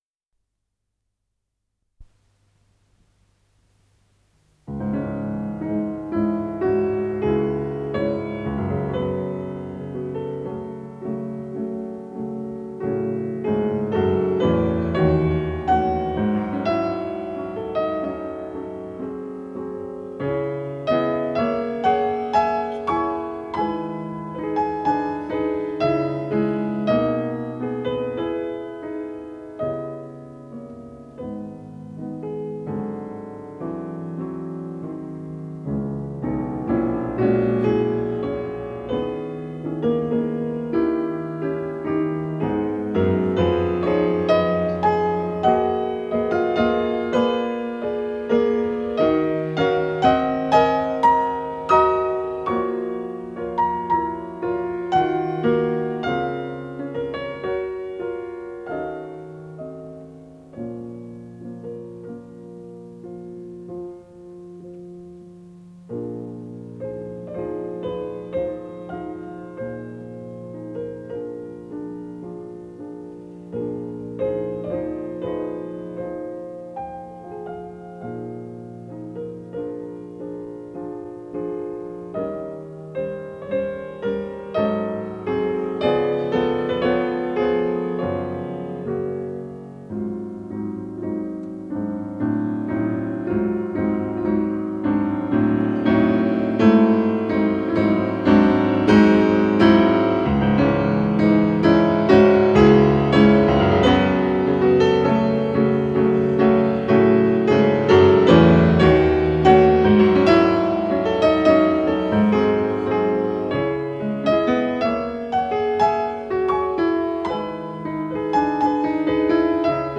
с профессиональной магнитной ленты
ПодзаголовокДо диез минор
ВариантДубль моно